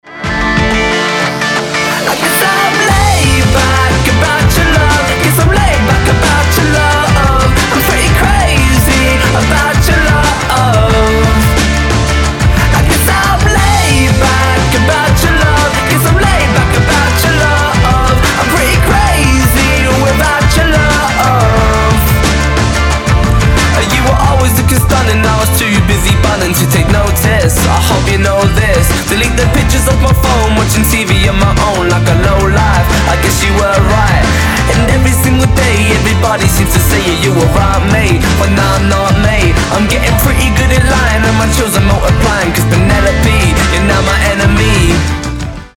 • Качество: 320, Stereo
позитивные
мужской вокал
indie rock